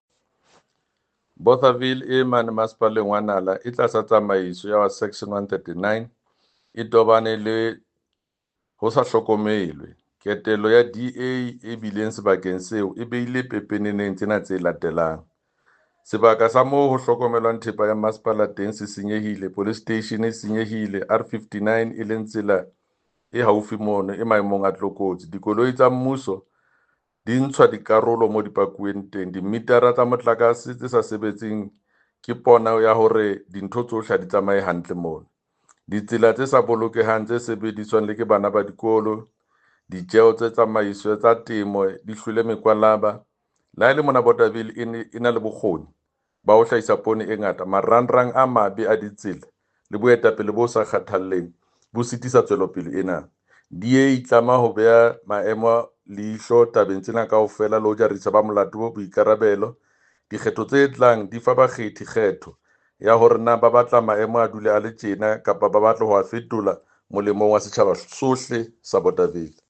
Sesotho soundbite by Jafta Mokoena MPL with images here, here, here, here, here and here